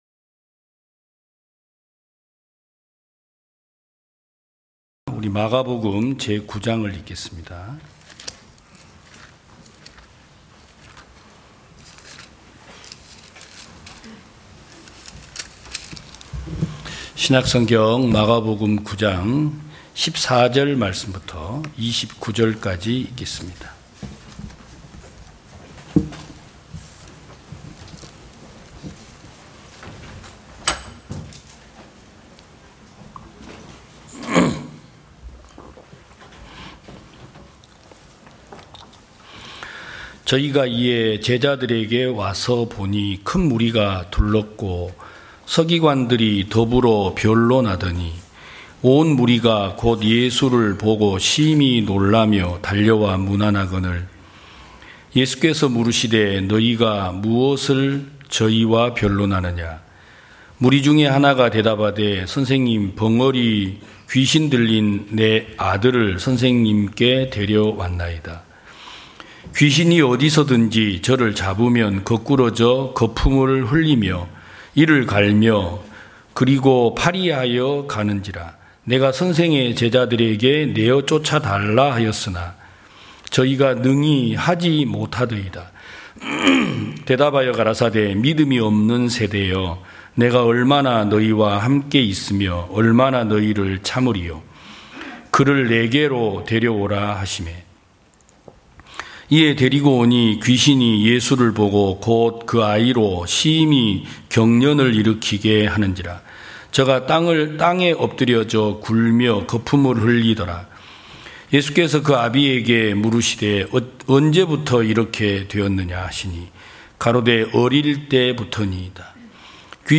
2022년 10월 02일 기쁜소식부산대연교회 주일오전예배
성도들이 모두 교회에 모여 말씀을 듣는 주일 예배의 설교는, 한 주간 우리 마음을 채웠던 생각을 내려두고 하나님의 말씀으로 가득 채우는 시간입니다.